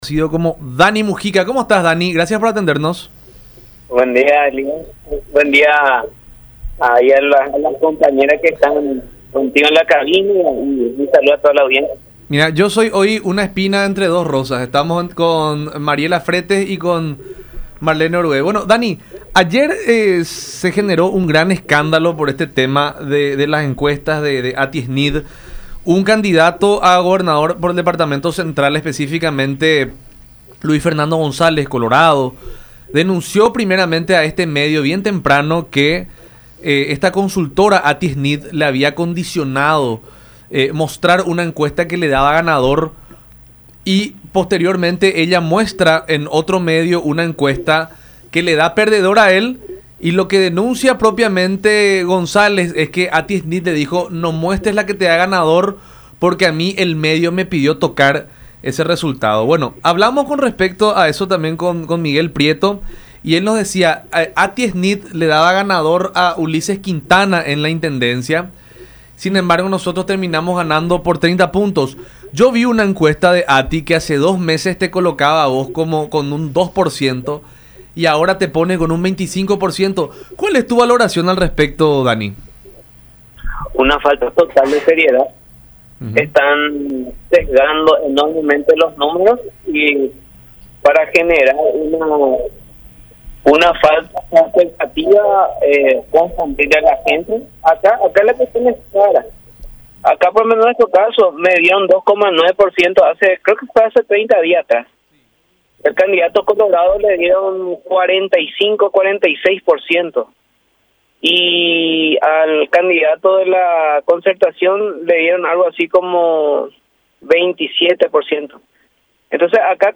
en diálogo con La Unión Hace La Fuerza a través de Unión TV y radio La Unión